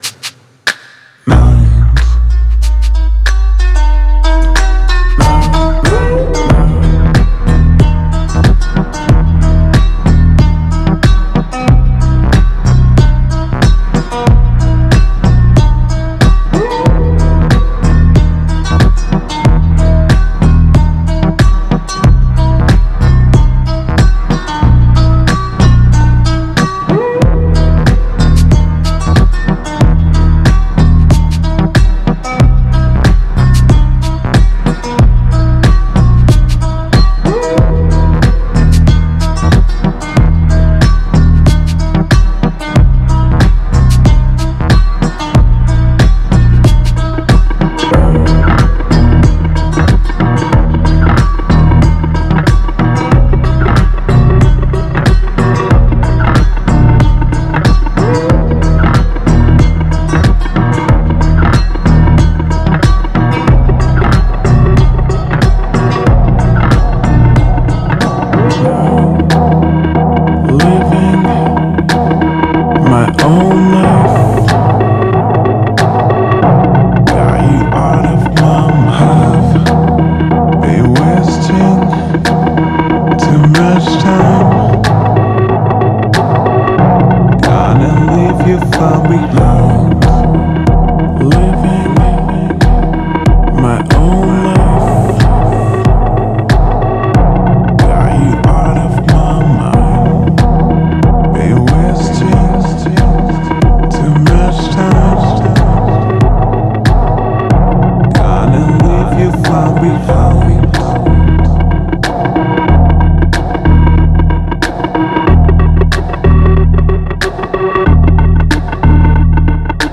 это трек в жанре электронной поп-музыки